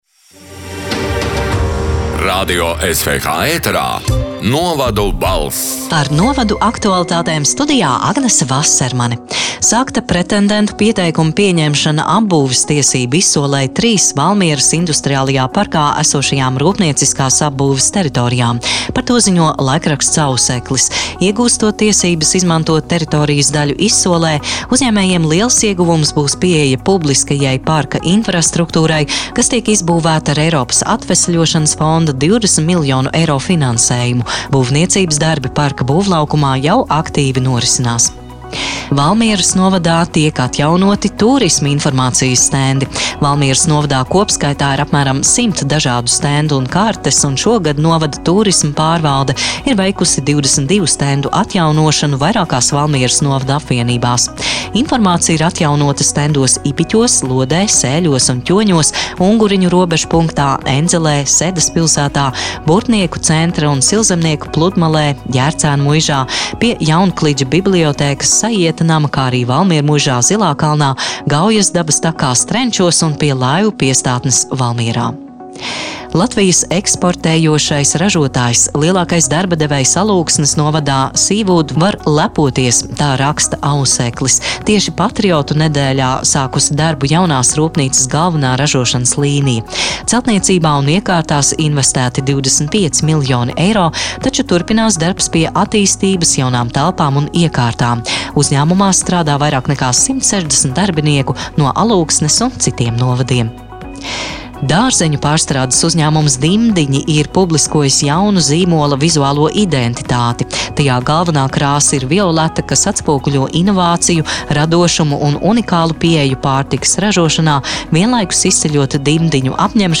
“Novadu balss” 27. novembra ziņu raidījuma ieraksts: